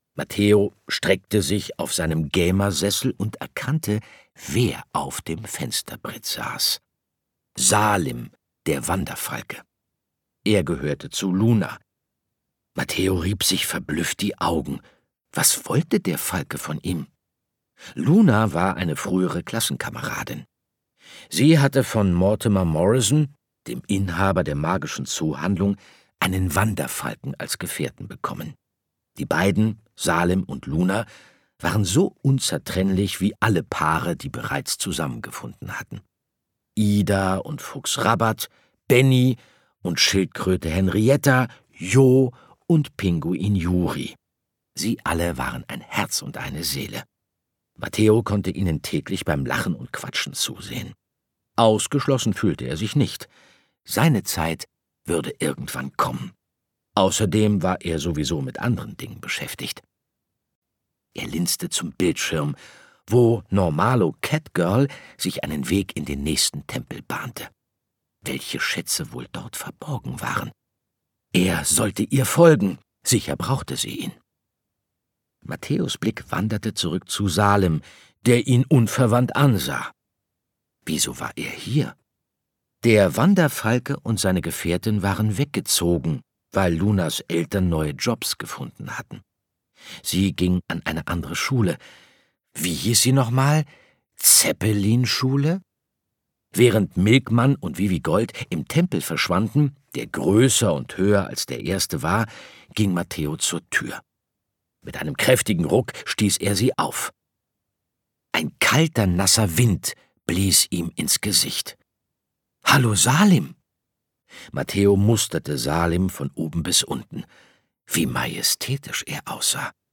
Details zum Hörbuch
Sprecher Andreas Fröhlich